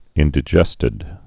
(ĭndĭ-jĕstĭd, -dī-)